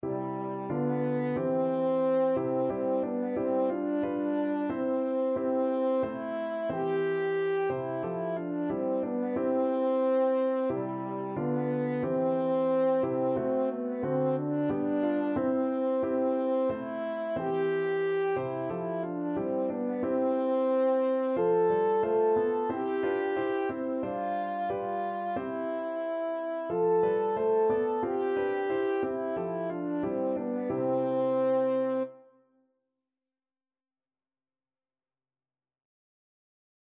French Horn
4/4 (View more 4/4 Music)
C major (Sounding Pitch) G major (French Horn in F) (View more C major Music for French Horn )
Traditional (View more Traditional French Horn Music)